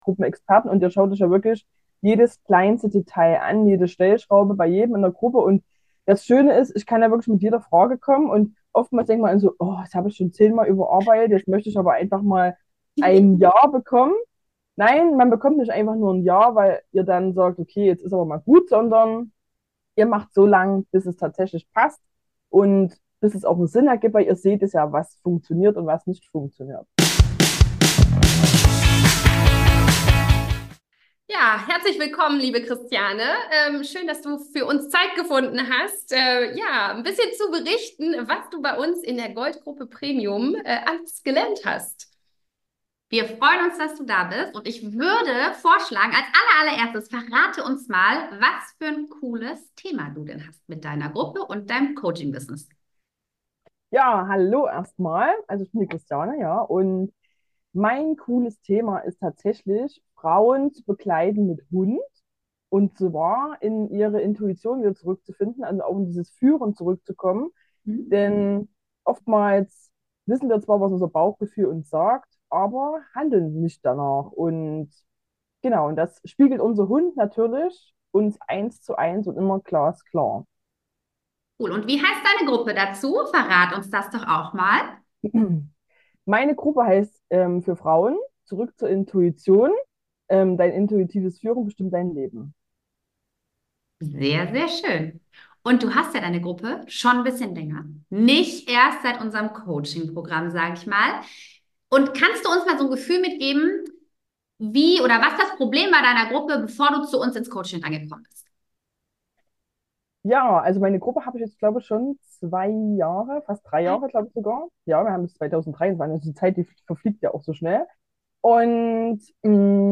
Mit Facebook Gruppen leichter verkaufen und Kunden gewinnen - Erfolgsinterview